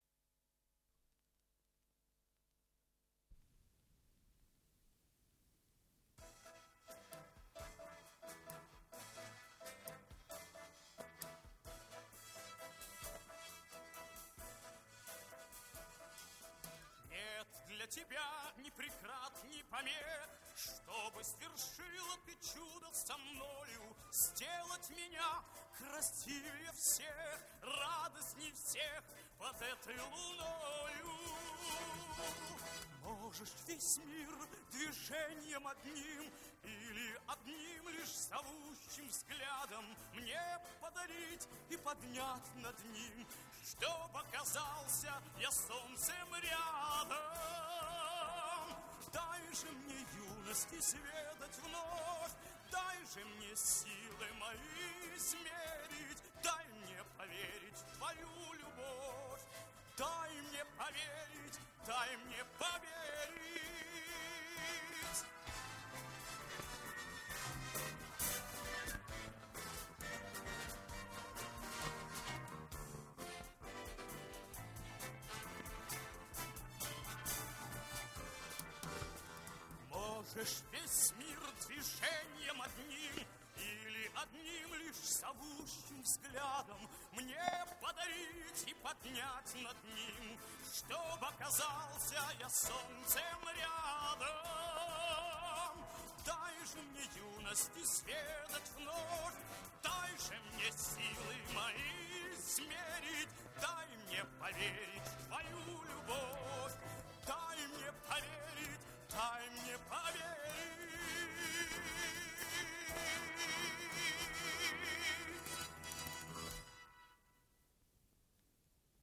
Дубль моно